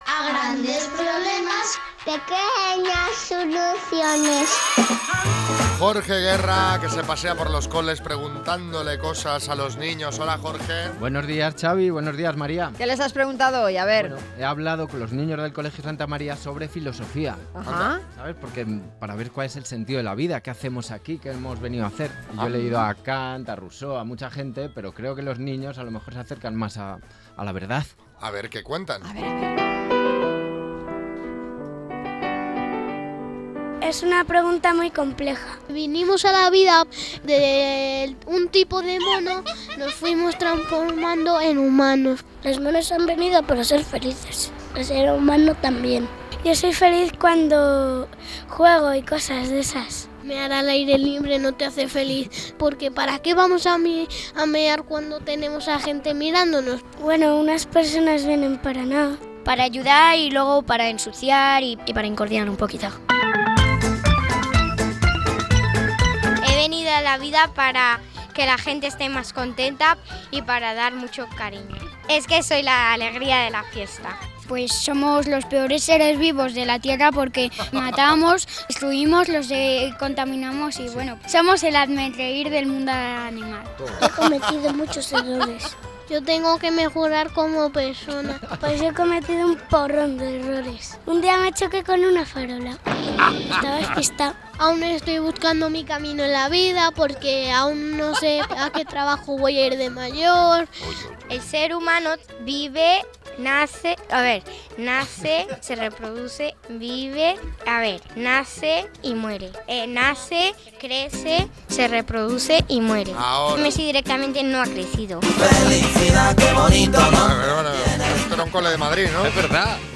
Los niños se ponen hoy filosóficos para responder a esta pregunta.
Los niños nos hablan hoy de filosofía, del sentido de la vida…